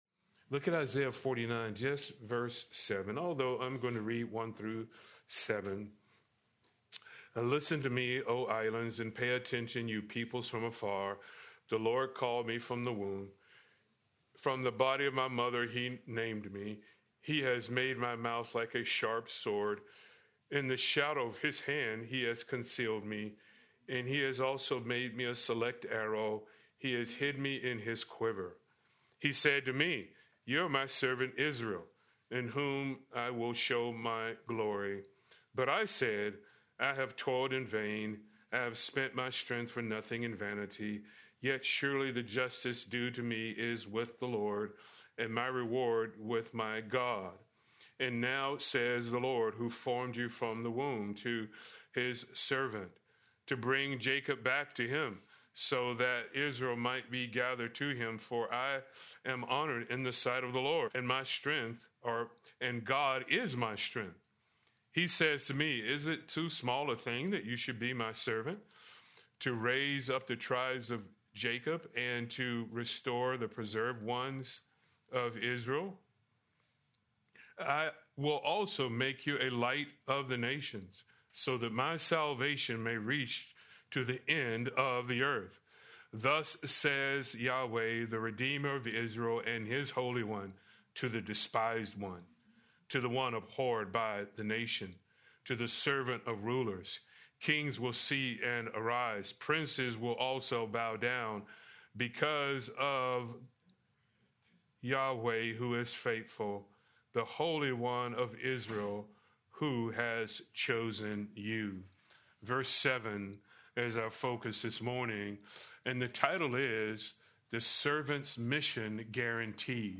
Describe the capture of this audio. Please note, due to technical difficulties, this recording skips brief portions of audio.